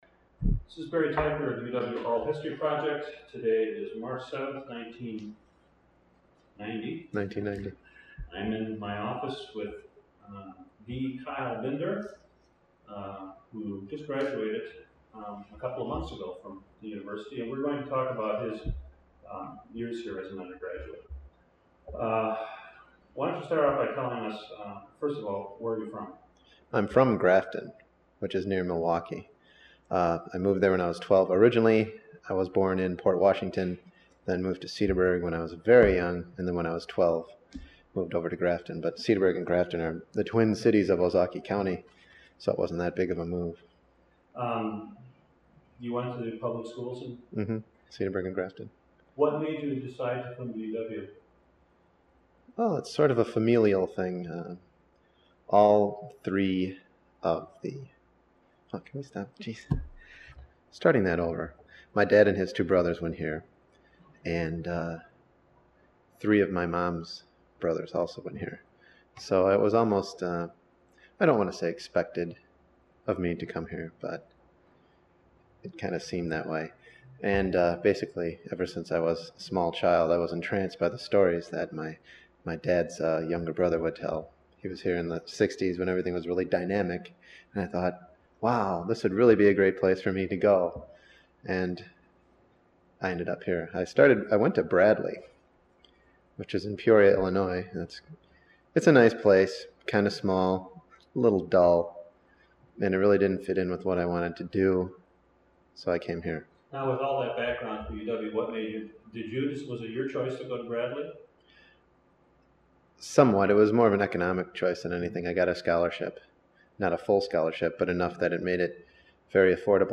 Oral History Interview: Hoofers Club (0378)